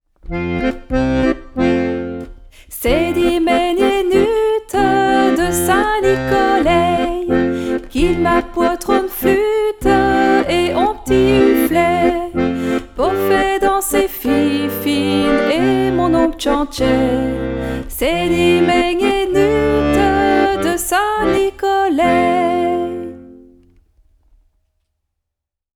chant
accordéon